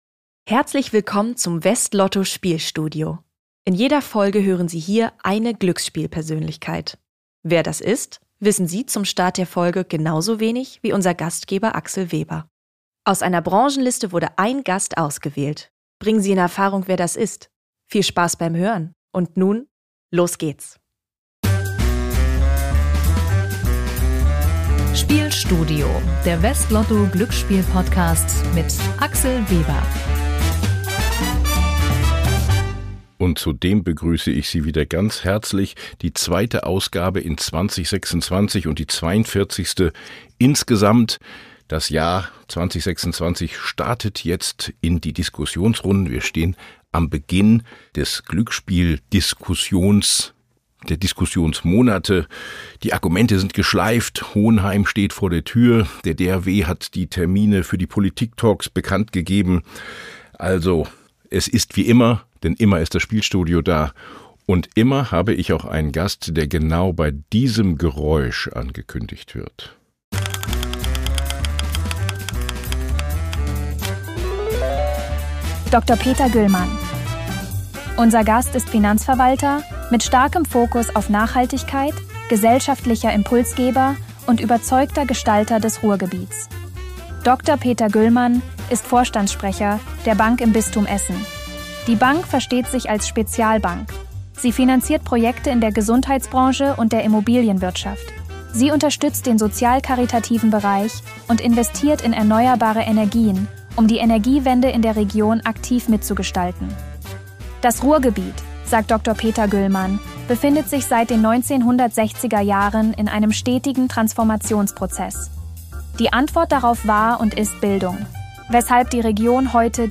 Außerdem geht es um die Frage, was Verantwortung in Zeiten von Automatisierung und KI bedeutet – und warum am Ende der Mensch die Verantwortung trägt. Ein Gespräch über Nachhaltigkeit, Demokratie und die Rolle von Unternehmen – zwischen Wertekompass, Regulierung und dem Anspruch, Gesellschaft konkret besser zu machen.
Hinweis: Ein Zuspieler in diesem Podcast wurde mit einer künstlichen Stimme erzeugt, die von einem KI-System erstellt wurde.